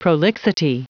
Prononciation du mot prolixity en anglais (fichier audio)
Prononciation du mot : prolixity